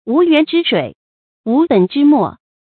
wú yuán zhī shuǐ，wú běn zhī mò
无源之水，无本之末发音